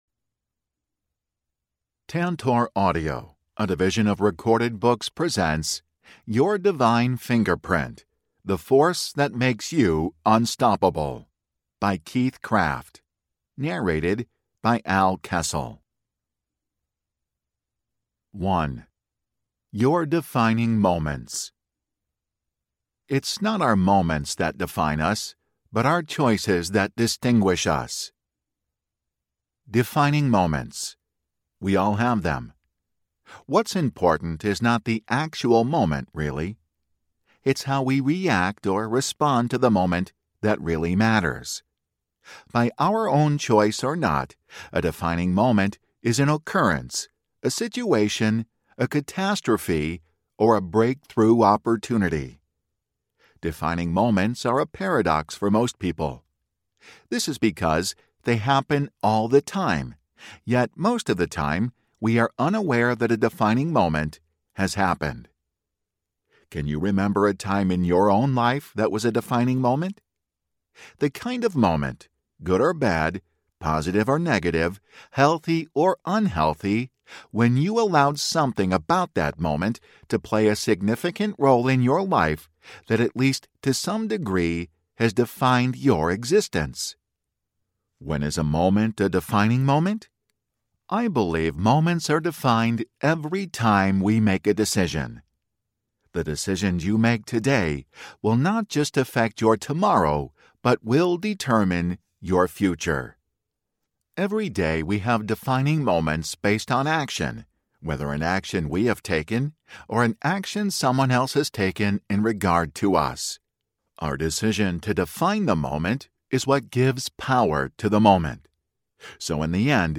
Your Divine Fingerprint Audiobook
Narrator
7.9 Hrs. – Unabridged